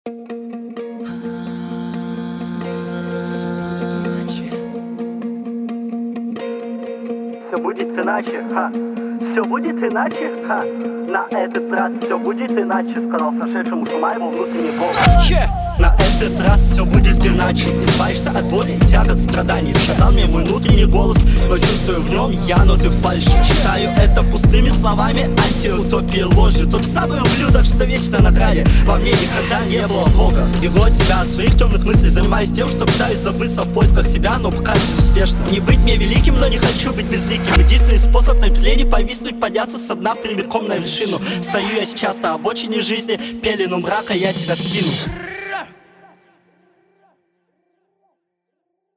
Очень странная, незвучащая ритмика без сочетаемости с инструменталом + нечёткое произношение.
Читаешь вопреки ритму бита.